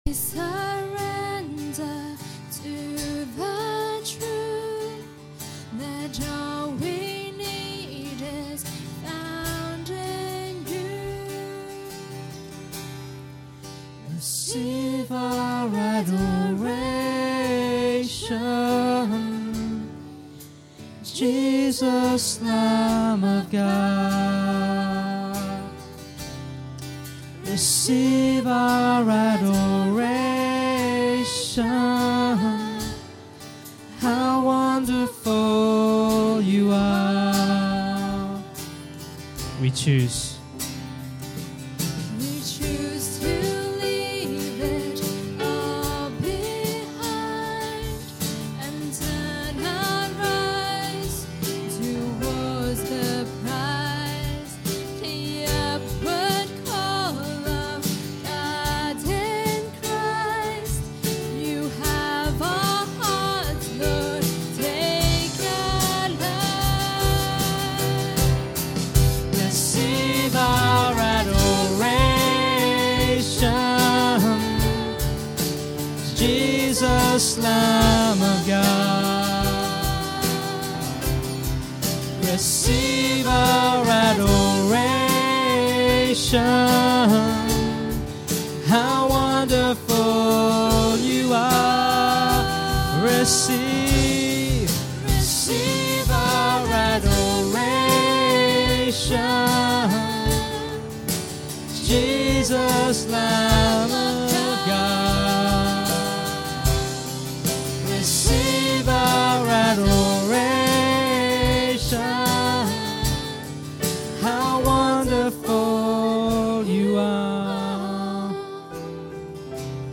Download Filename 130707Worship.mp3 filesize 46.81 MB Version 1.0 Date added 1 January 2015 Downloaded 1310 times Category Worship Sets Tags 2013